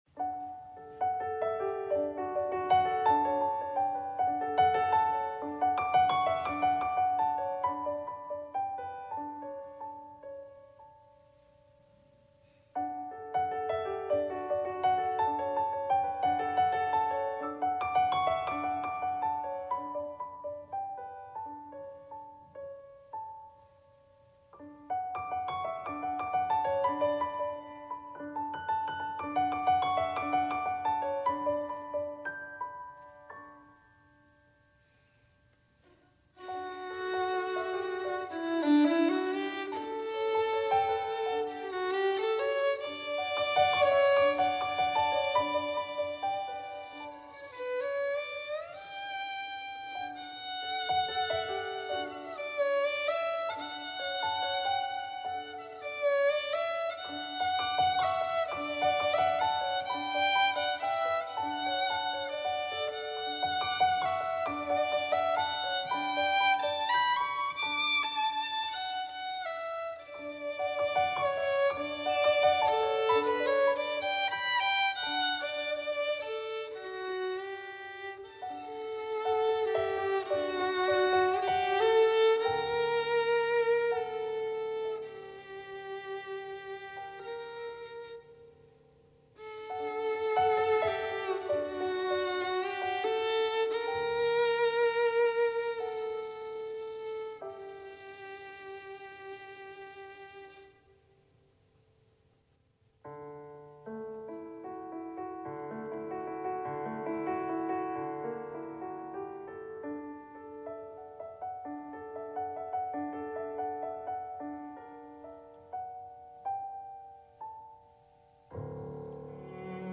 trio of soloists
piano. Cellist
makes a special appearance as violinist.